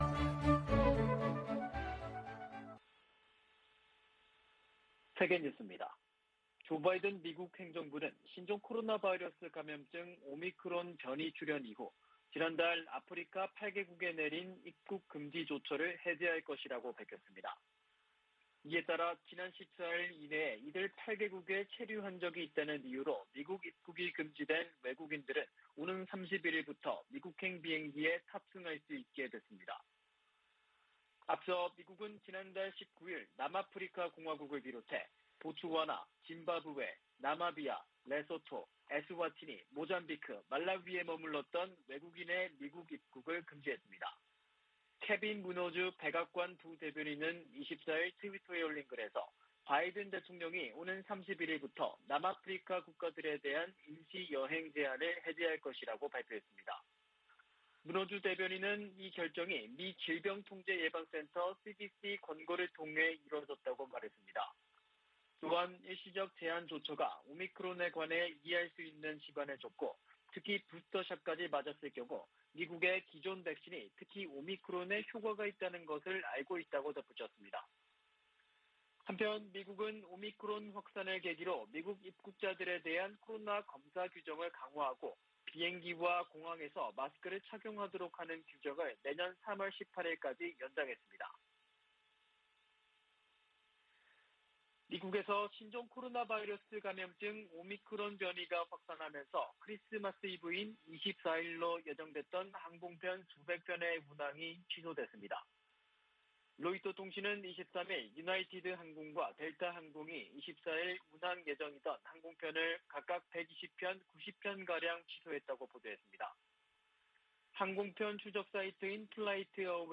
VOA 한국어 아침 뉴스 프로그램 '워싱턴 뉴스 광장' 2021년 12월 25일 방송입니다. 미국 내 구호단체들은 올 한 해가 대북 지원 사업에 가장 도전적인 해였다고 밝혔습니다. 미 델라웨어 소재 ‘TD 뱅크그룹’이 대북 제재 위반 혐의로 11만5천 달러 벌금에 합의했다고 미 재무부가 밝혔습니다. 한국과 중국이 4년 반 만에 가진 외교차관 전략대화에서 종전선언 등에 협력 방안을 논의했습니다.